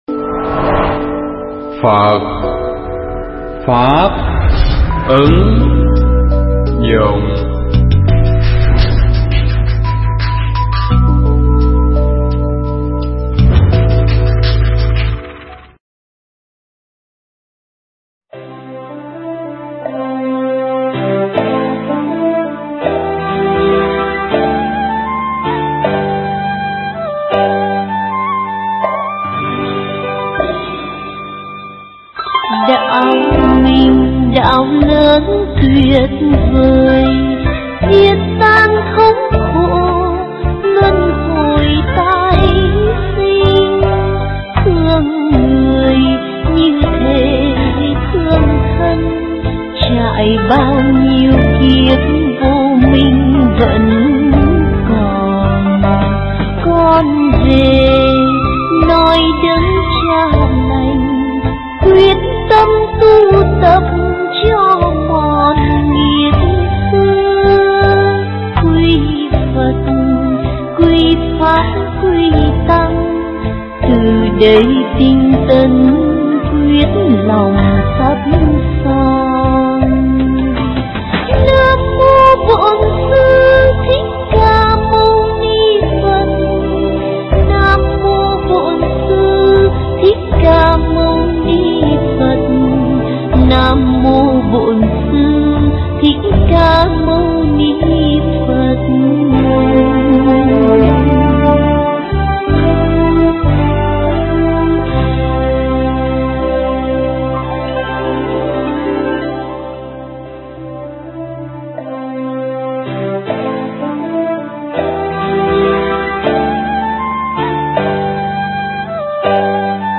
thuyết giảng bài pháp thoại Thời Mạt Pháp tại chùa Thiên Hòa